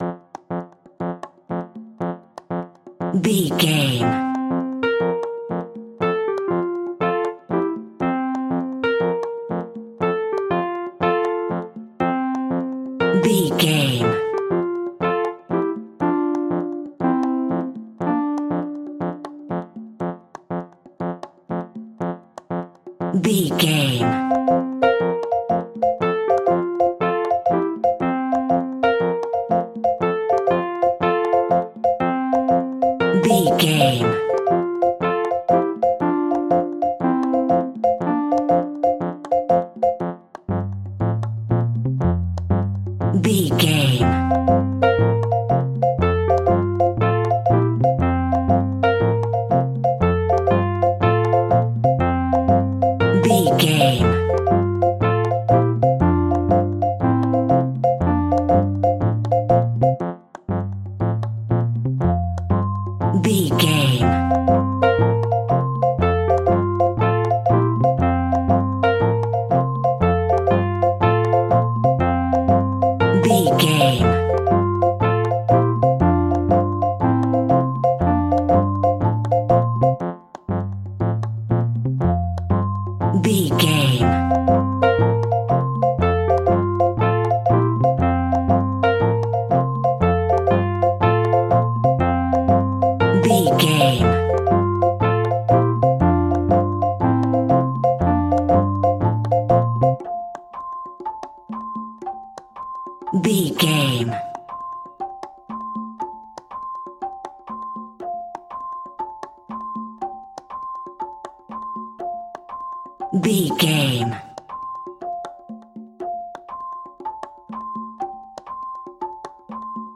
Ionian/Major
instrumental music
electronic
drum machine
synths
strings
brass